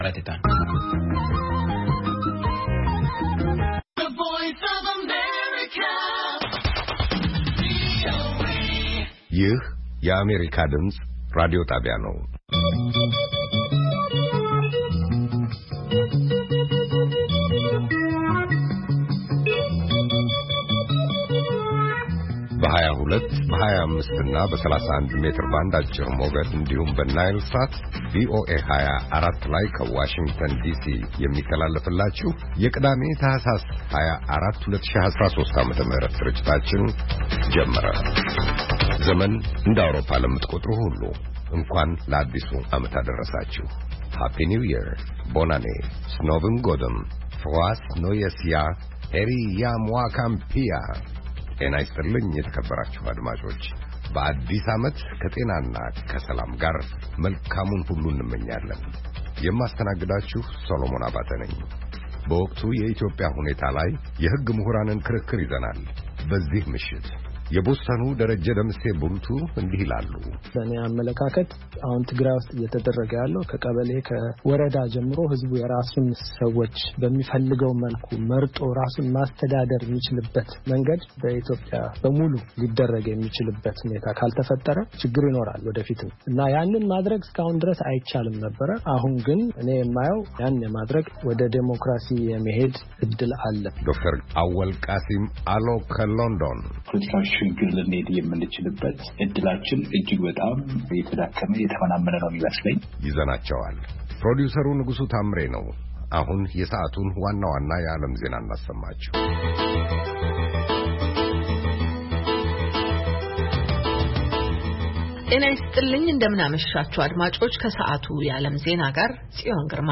ቅዳሜ፡-ከምሽቱ ሦስት ሰዓት የአማርኛ ዜና
ቪኦኤ በየዕለቱ ከምሽቱ 3 ሰዓት በኢትዮጵያ አቆጣጠር ጀምሮ በአማርኛ፣ በአጭር ሞገድ 22፣ 25 እና 31 ሜትር ባንድ የ60 ደቂቃ ሥርጭቱ ዜና፣ አበይት ዜናዎች ትንታኔና ሌሎችም ወቅታዊ መረጃዎችን የያዙ ፕሮግራሞች ያስተላልፋል። ቅዳሜ፡- ከዚህም ከዚያም፤ የሙዚቃ ቃና